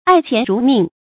爱钱如命 ài qián rú mìng
爱钱如命发音